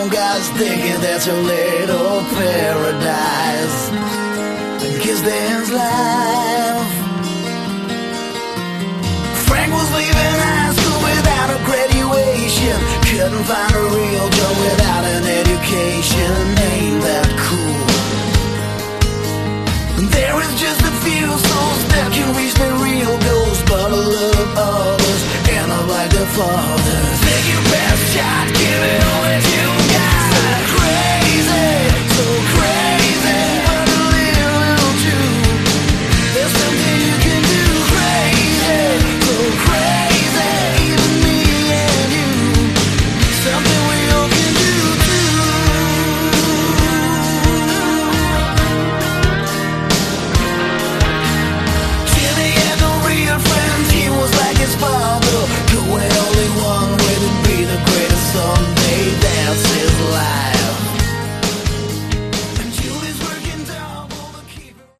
Category: Melodic Hard Rock
Vocals, Bass, Guitar, Keyboards
Drums, Backing Vocals